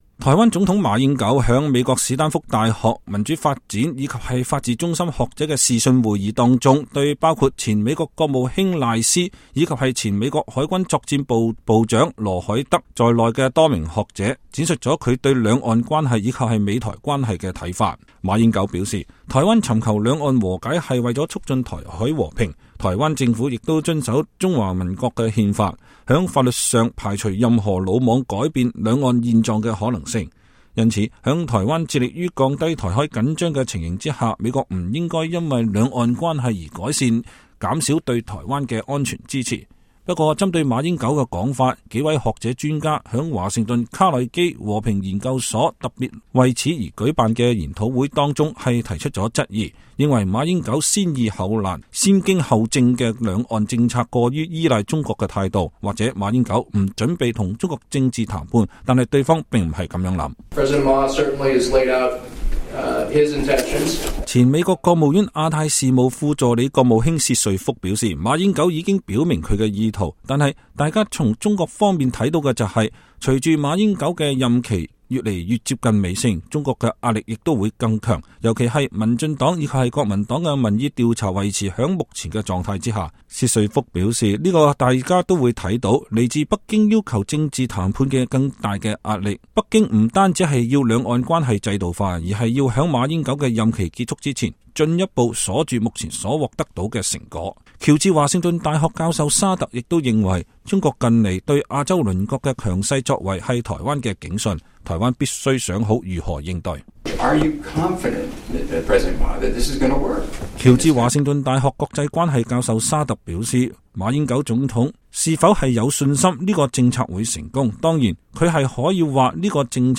馬英九視訊演說，指兩岸和解符合美台利益